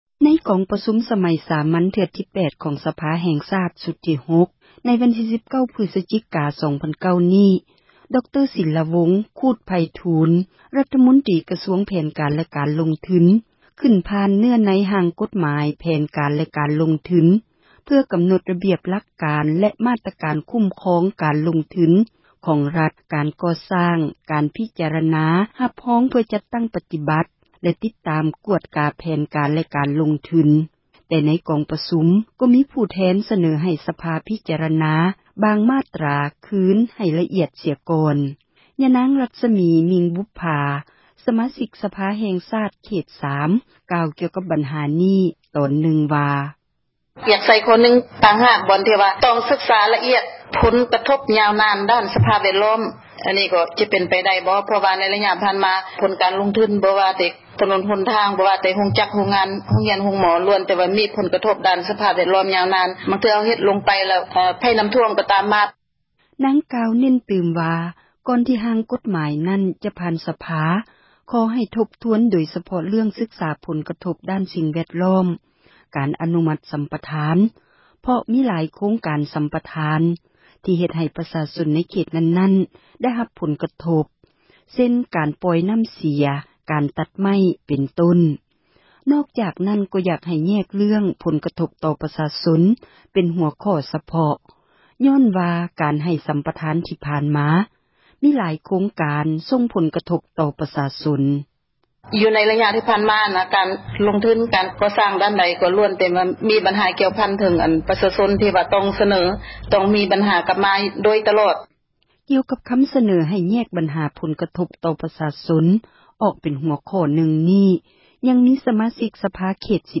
ຍານາງ ຣັສມີ ມິ່ງບຸບຜາ ສະມາຊິກສະພາແຫ່ງຊາດ ເຂດ 3 ກ່າວກ່ຽວກັບບັນຫານີ້ ໃນຕອນນຶ່ງວ່າ: